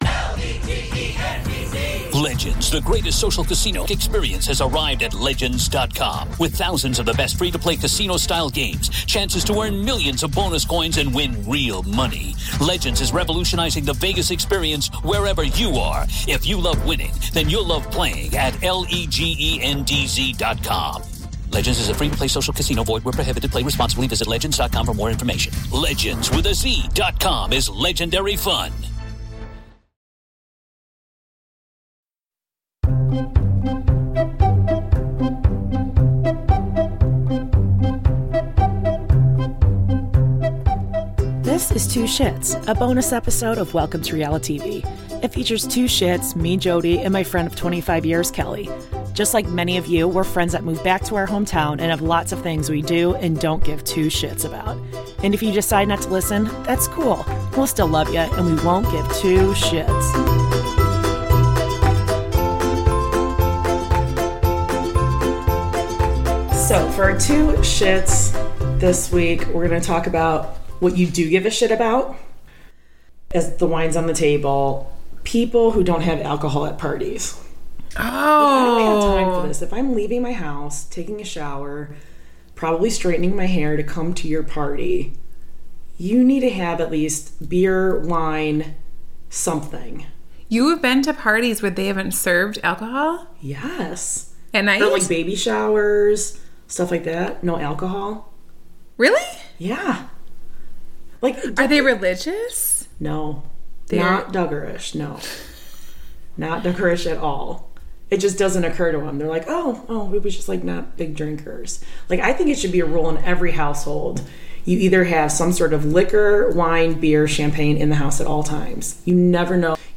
Two lifelong friends, talking over drinks, as friends living back in their hometowns do.